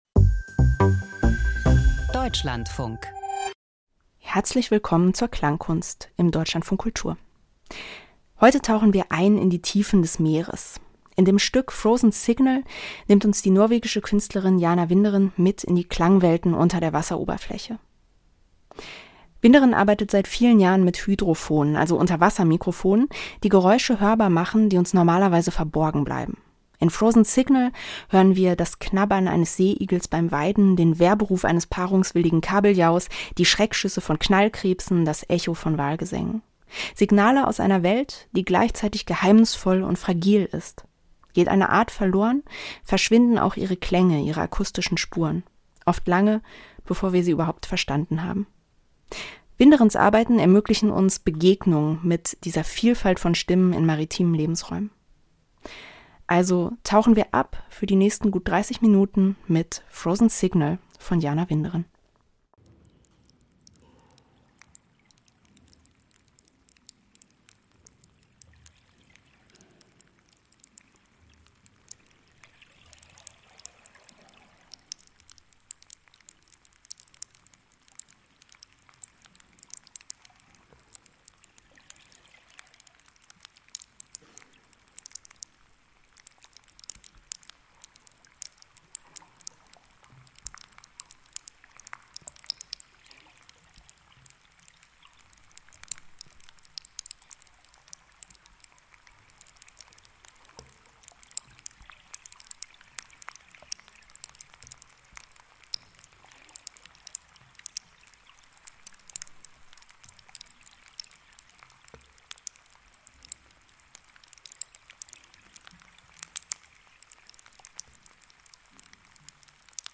Klangkunst mit Unterwasseraufnahmen - Frozen Signal
Klangkomposition über eine akustische Parallelwelt, deren Existenz bedroht ist.